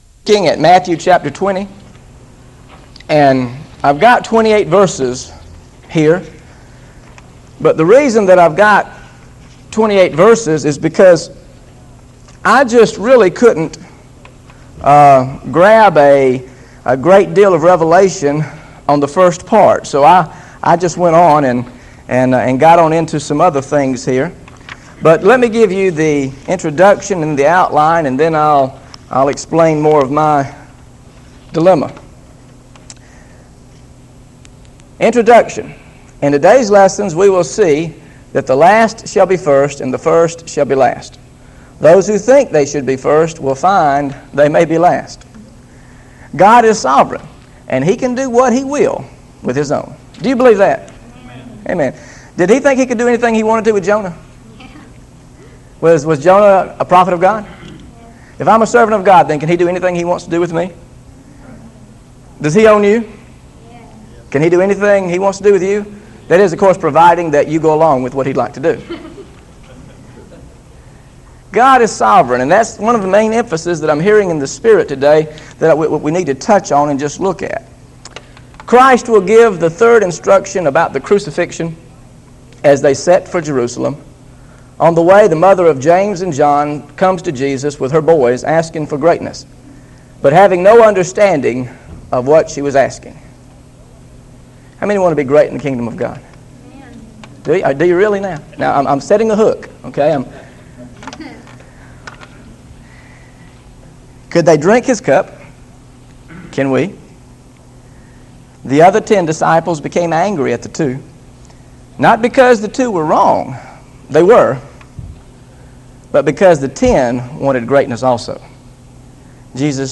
GOSPEL OF MATTHEW BIBLE STUDY SERIES This study of Matthew: The Last Shall Be First Understanding Matthew 20 Verses 1-28 is part of a verse-by-verse teaching series through the Gospel of Matthew.